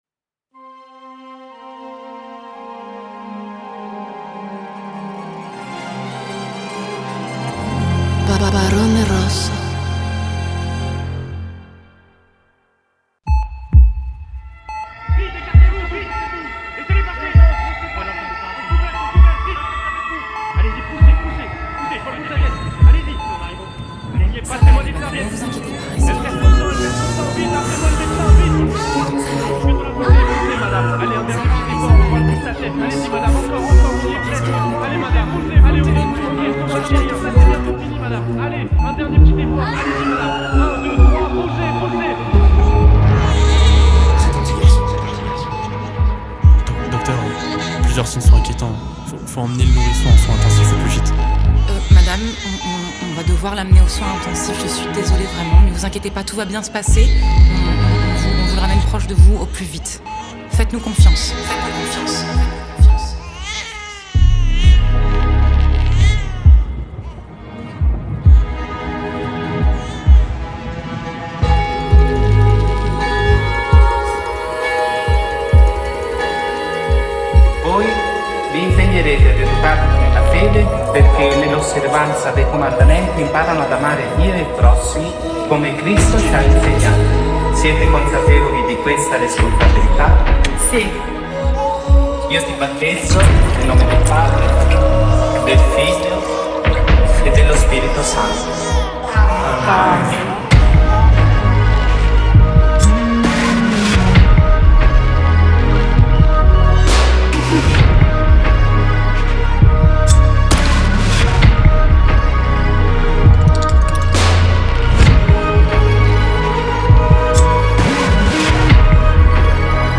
french rap, pop urbaine Télécharger